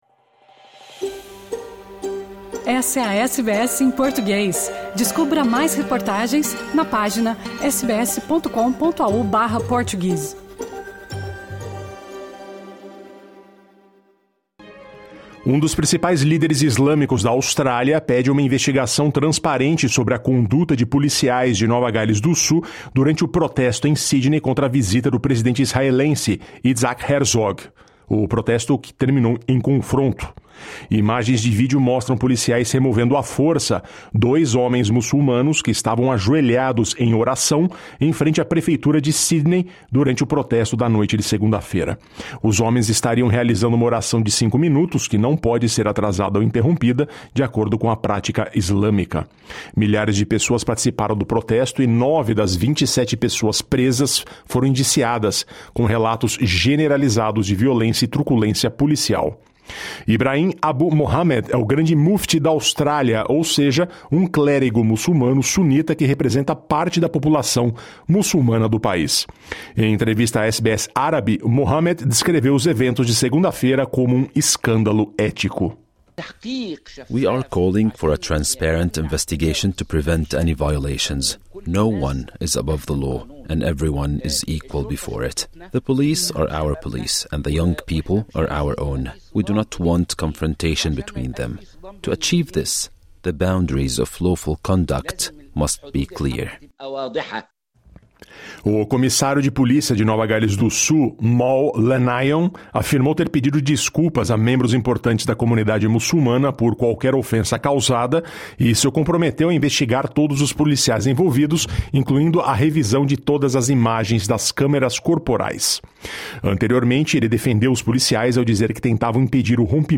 Notícias da Austrália e do Mundo | Quarta-feira, 11 de fevereiro